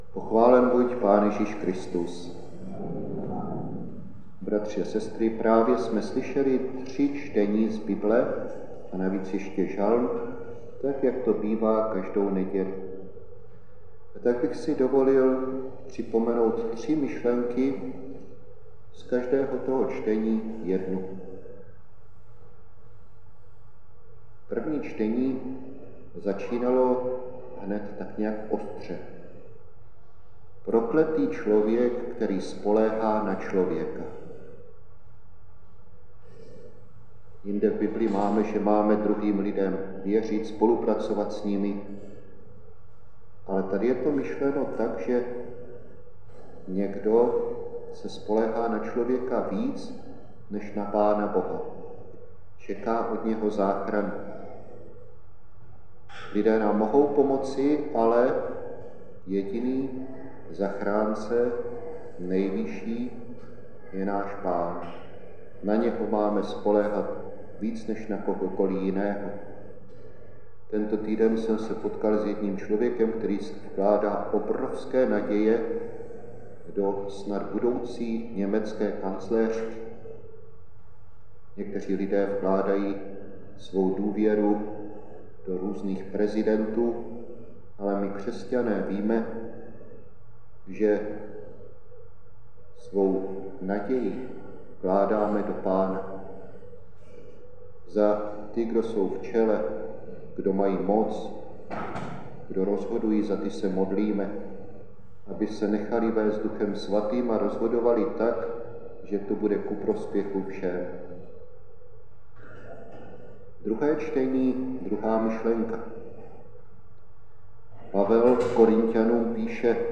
Kázání z 6. neděle v mezidobí (6.6 min.)